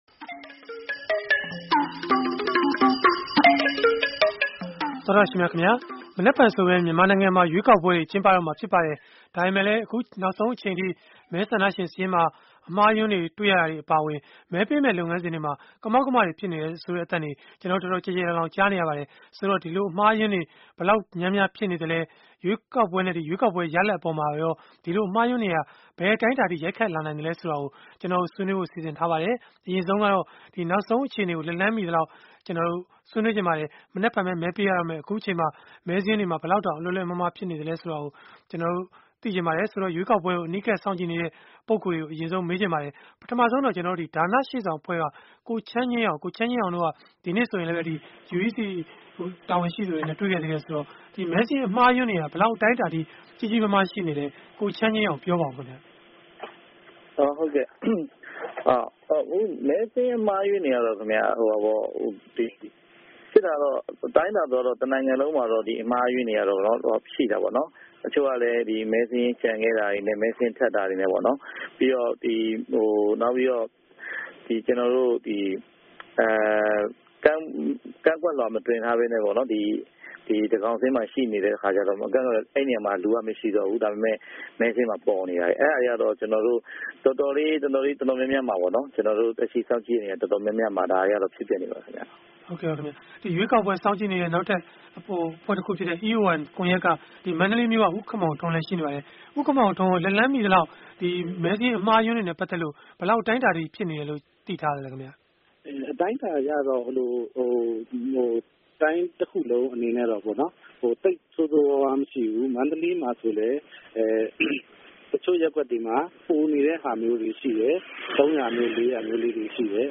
စနေနေ့ည တိုက်ရိုက်လေလှိုင်း အစီအစဉ်